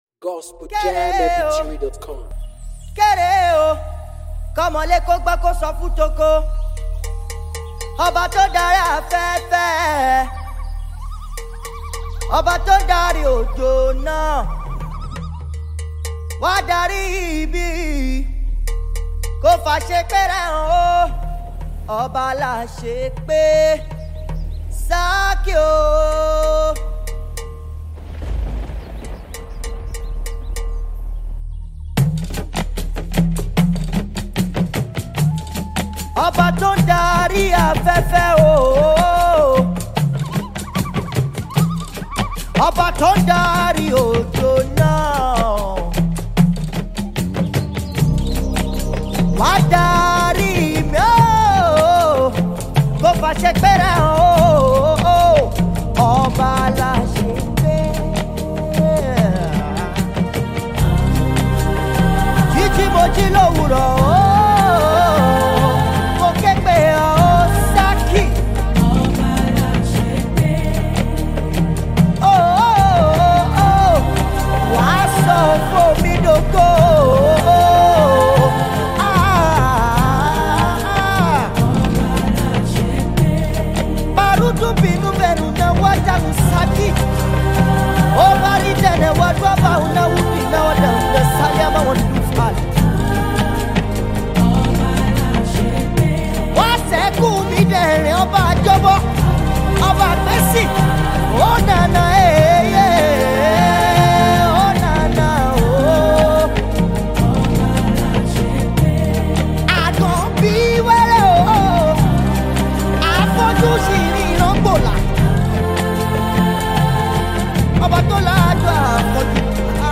Nigerian Gospel musician